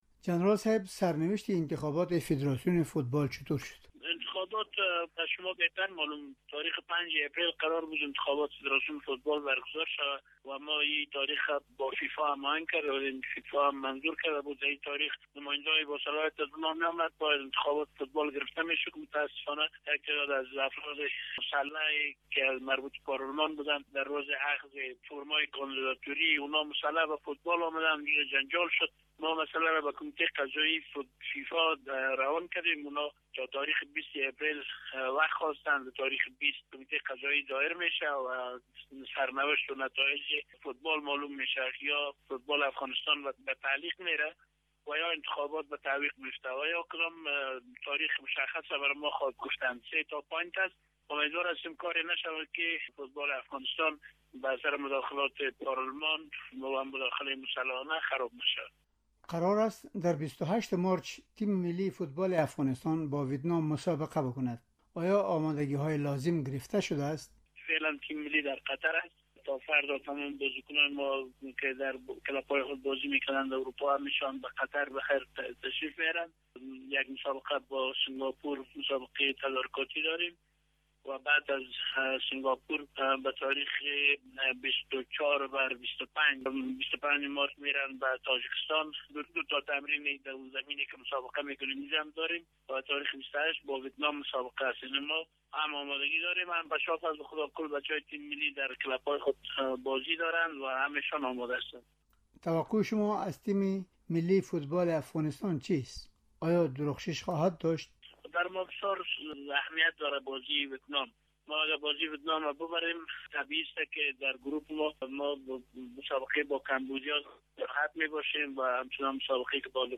مصاحبۀ